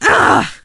shelly_hurt_04.ogg